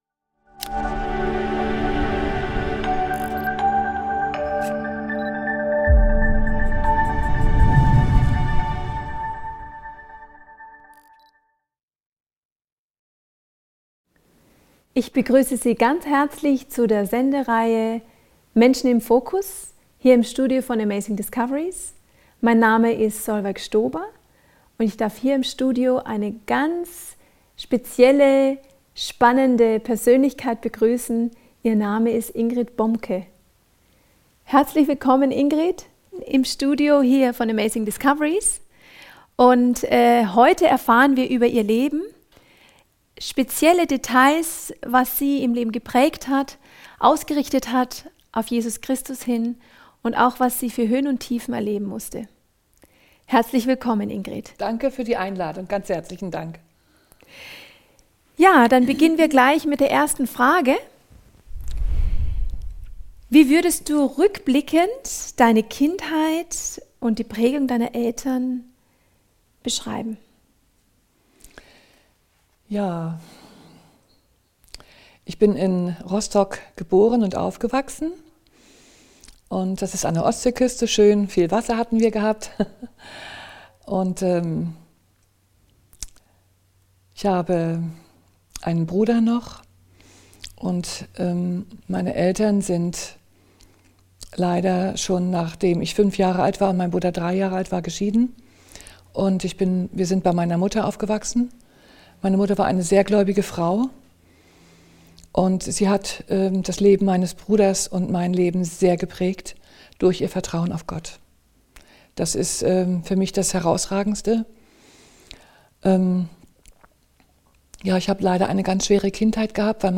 Zeugnis